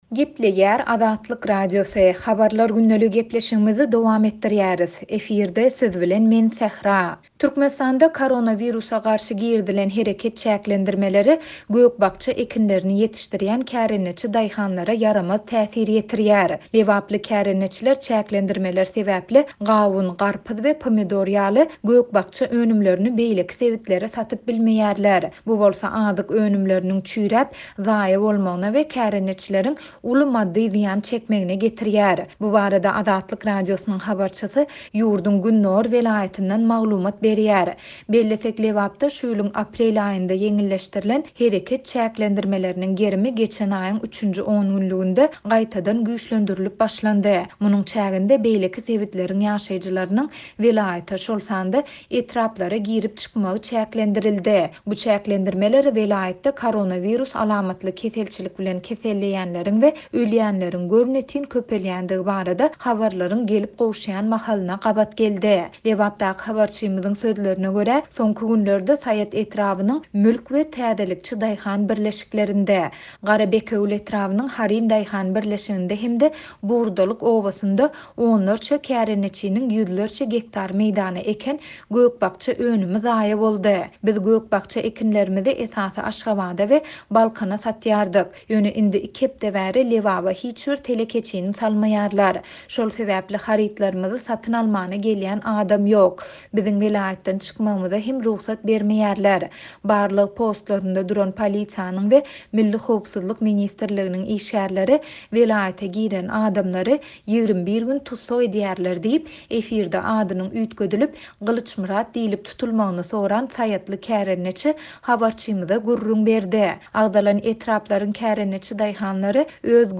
Bu barada Azatlyk Radiosynyň habarçysy ýurduň gündogar welaýatyndan maglumat berýär.